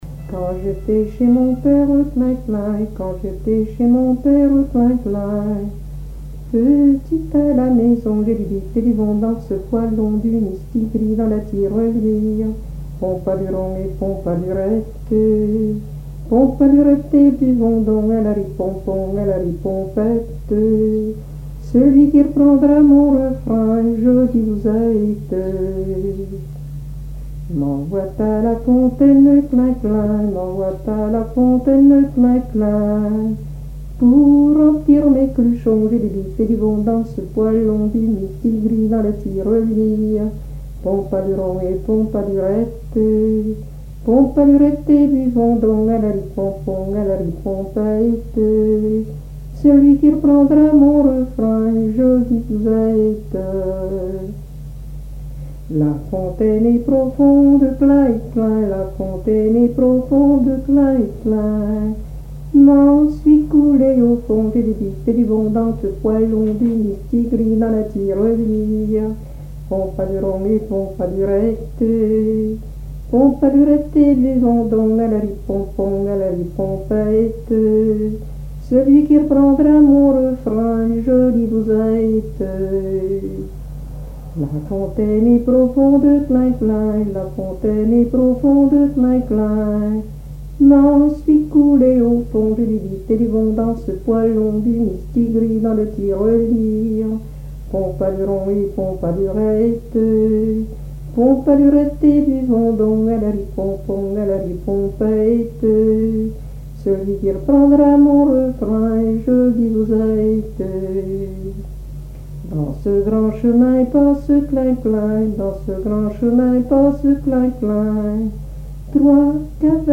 Mémoires et Patrimoines vivants - RaddO est une base de données d'archives iconographiques et sonores.
danse : ronde : grand'danse
Genre laisse
Pièce musicale inédite